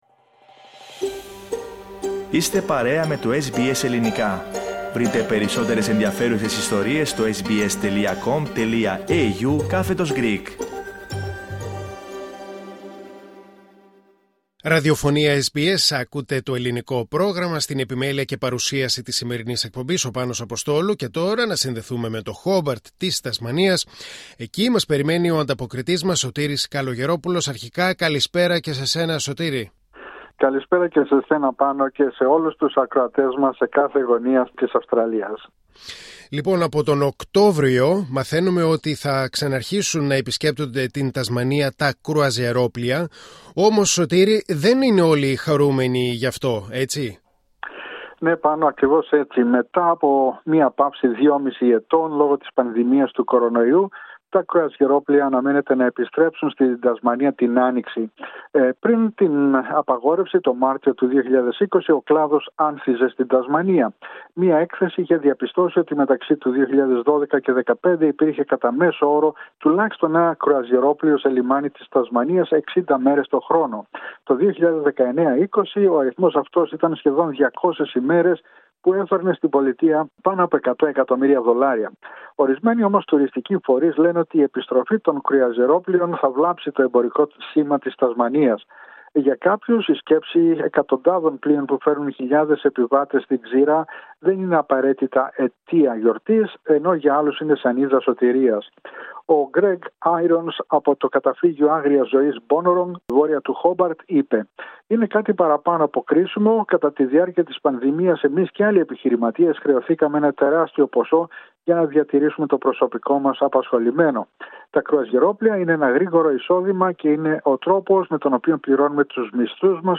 H εβδομαδιαία ανταπόκριση από το Χόμπαρτ της Τασμανίας.